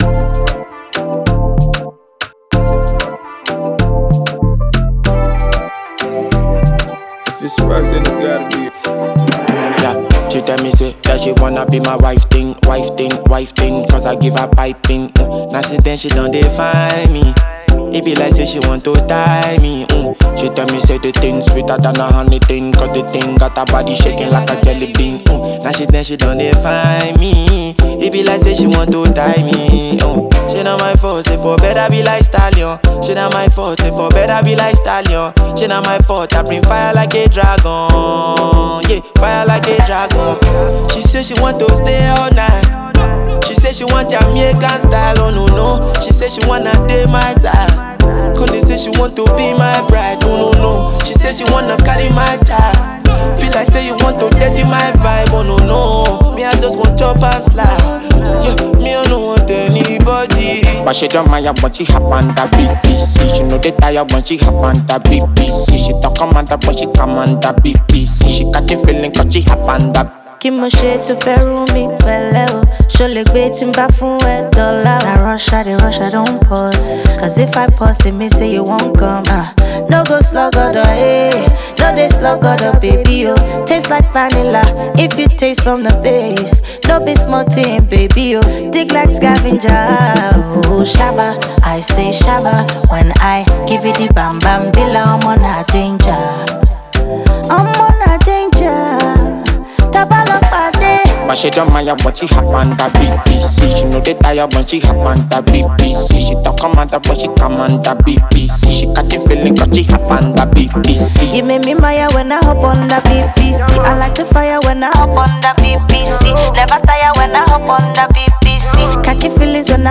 rapper
radio and club-friendly Jam